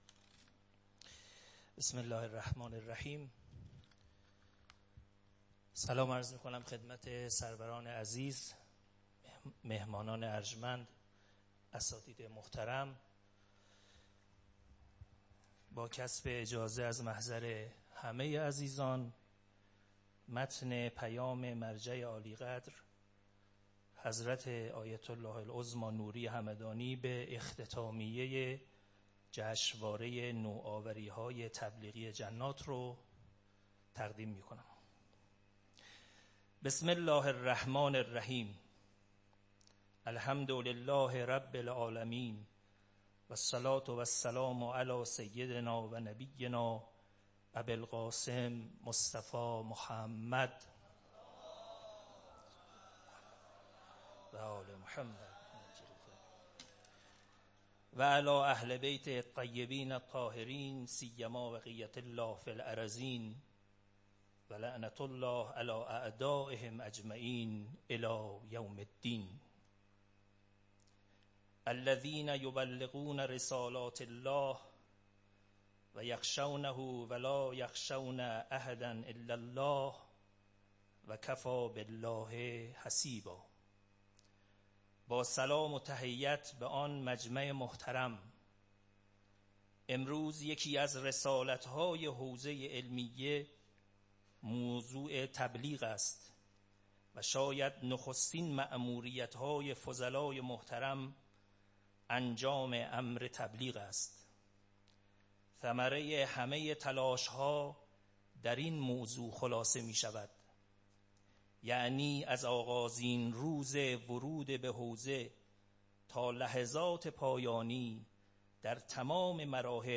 صوت| قرائت پیام مرجع عالیقدر حضرت آیت الله العظمی نوری همدانی (دامت برکاته)